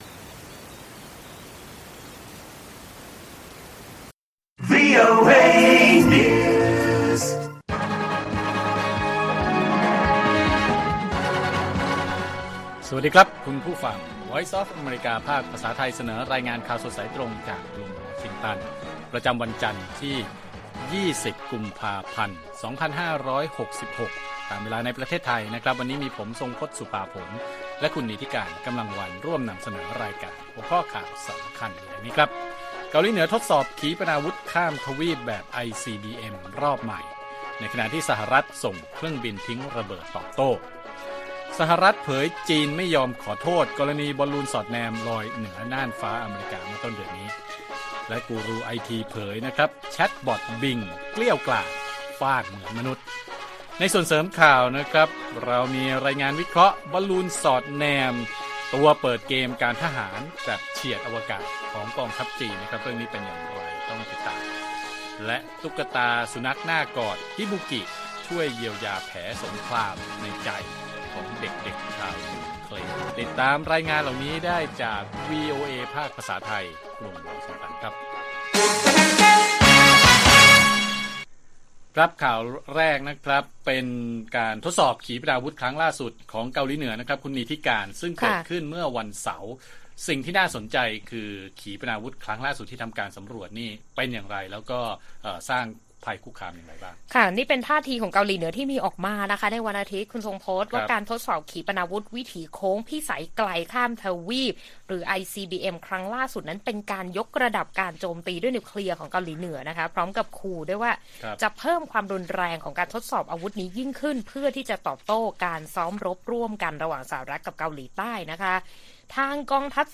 ข่าวสดสายตรงจากวีโอเอไทย 8:30–9:00 น. วันที่ 20 ก.พ. 2566